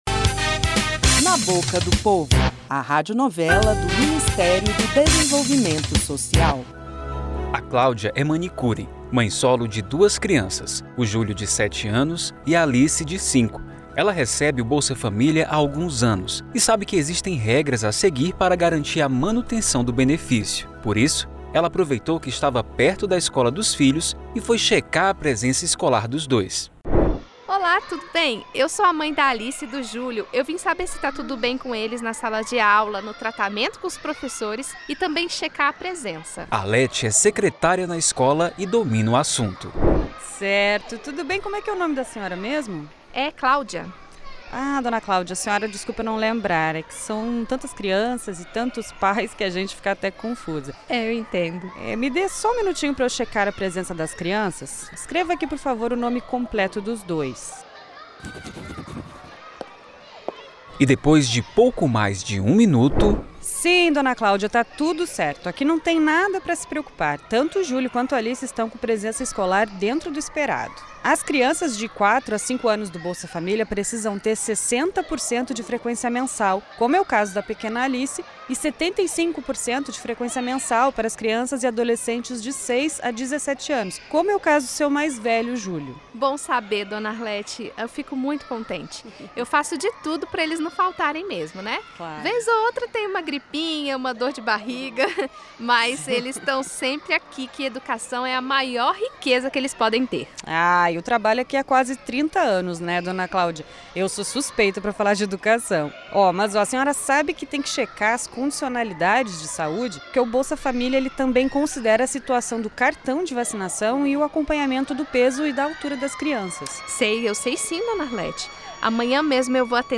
Na Boca do Povo - Radionovela